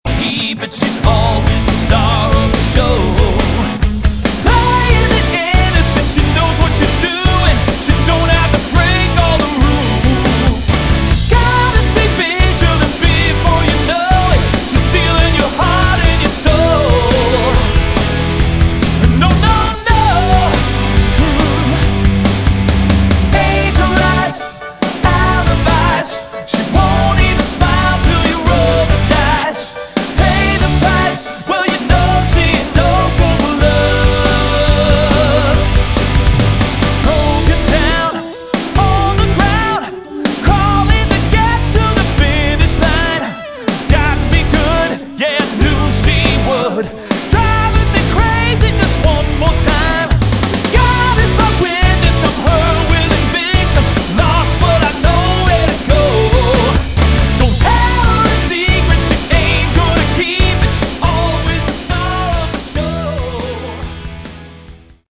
Category: AOR / Melodic Rock
vocals
drums
bass
guitars
keyboards